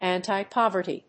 音節ànti・póverty 発音記号・読み方
/ˌæntaɪˈpɑvɝti(米国英語), ˌæntaɪˈpɑ:vɜ:ti:(英国英語)/
フリガナアンタイパバーティー